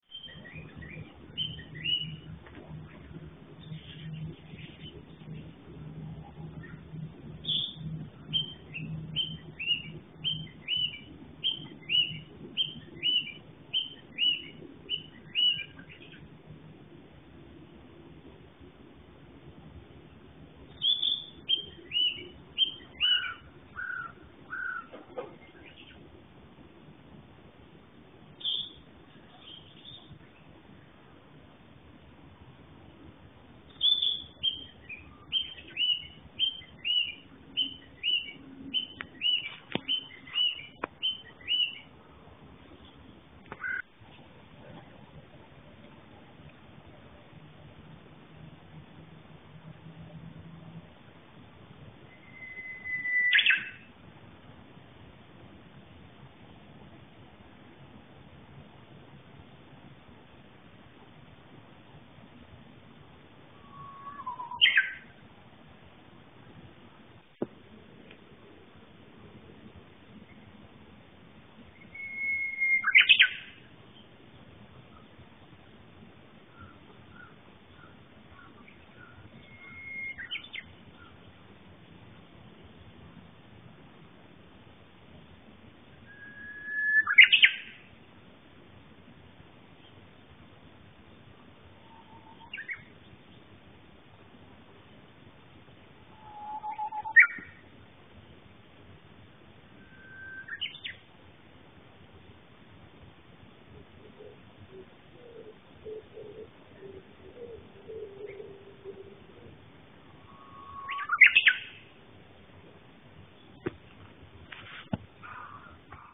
朝の散歩今は5時になったら外は明るい、遊歩道には鳥の声が、さわやかに聞こえます。
時折カラスのカァカァが入りますが。
曼陀羅山鳥の声.mp3